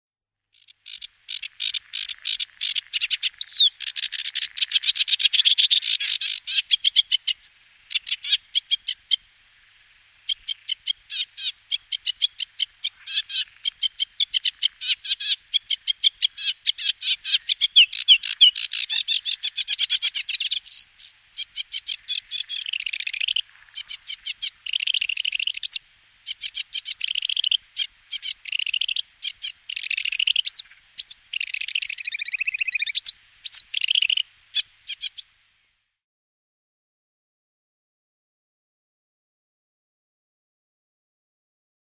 Schilfrohrsänger
Sein Gesang ist wie bei allen Rohrsängern eher weniger ein Liedchen, als eine Aneinanderreihung kratzender und quitschender Geräusche, sowie kurze Imitations-Einlagen anderer Vögel. Im Gegensatz zum Teich- und Drosselrohrsänger ist der Gesang vom Schilfrohrsänger eher schwätzig, nicht so klar rhytmisch strukturiert und ohne klares Muster. Den Klang würde ich als höheres Schnarren und Ticksen mit eingeworfenem Piepen und Laserkanonen beschreiben
Hier ist eine Aufnahme vom DK-Verlag, verlinkt aus dem Buch “Vögel in Europa”